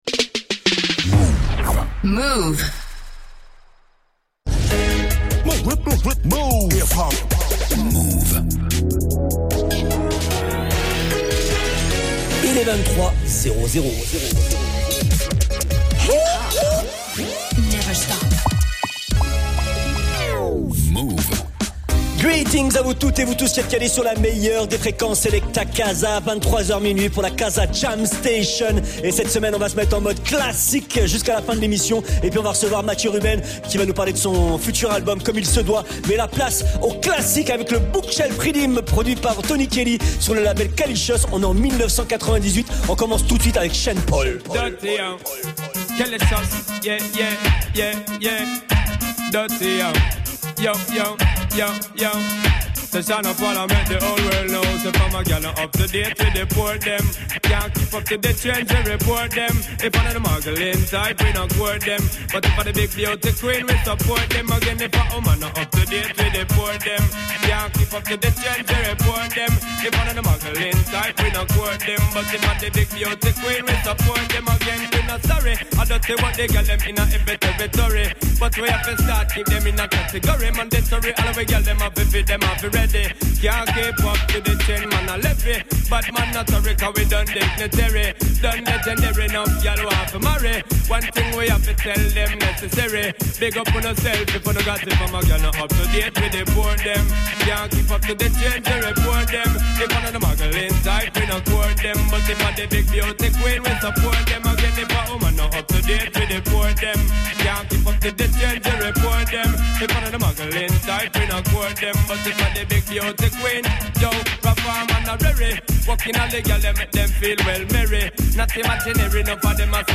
Classic Dancehall